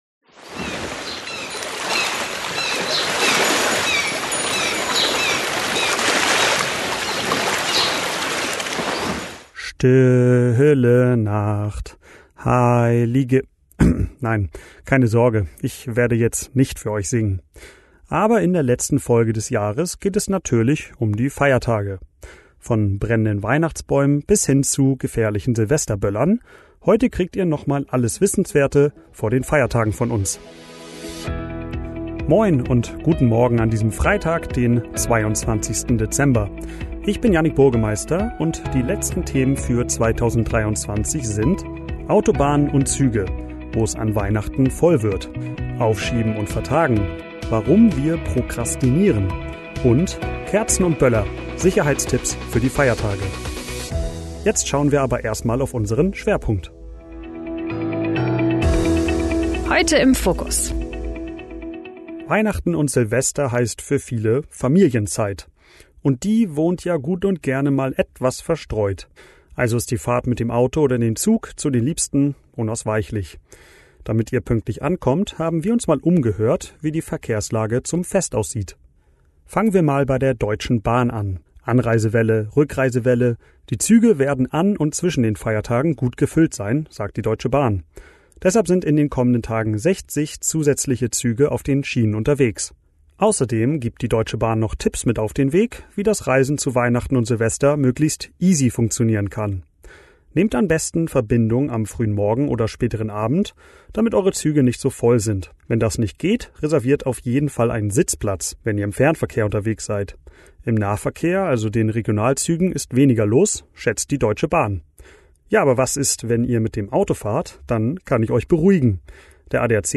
Dein News-Podcast für Schleswig-Holstein
Nachrichten